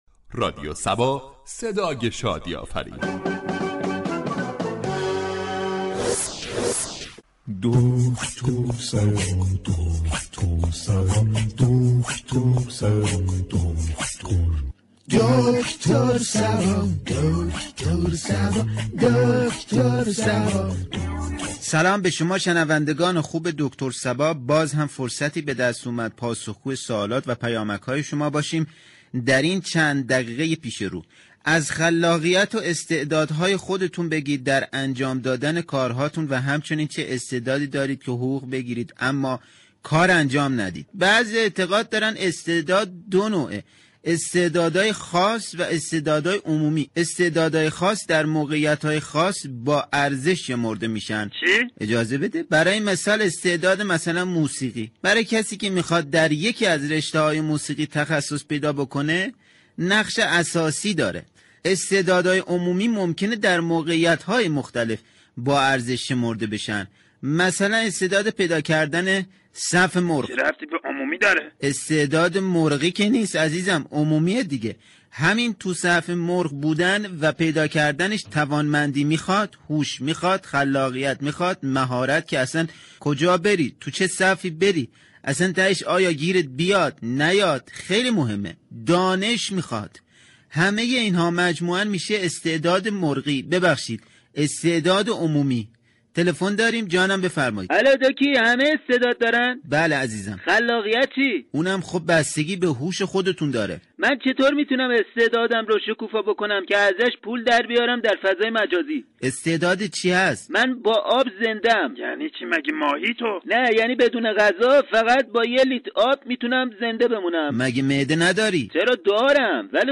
برنامه طنز "دكتر صبا " با بیان مسایل اجتماعی و فرهنگی با نگاهی طنز برای مخاطبان نسخه شادی و لبخند می پیچید.